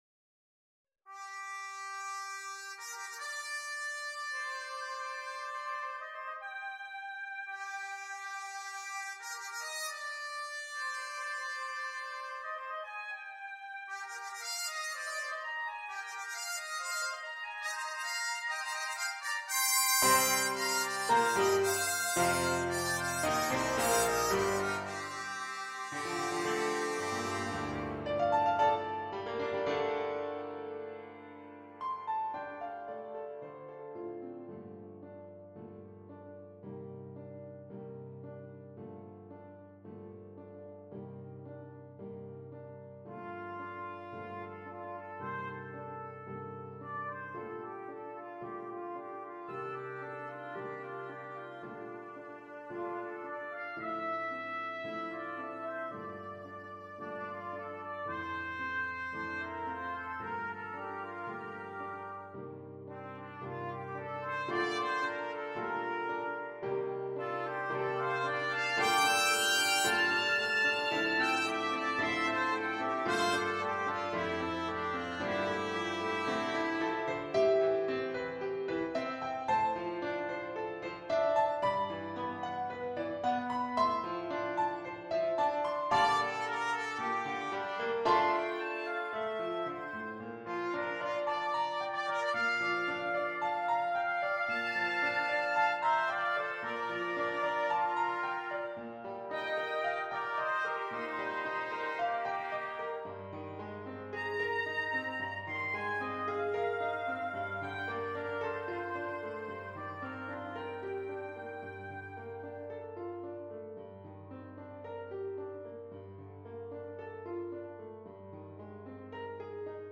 for oboe, trumpet, and piano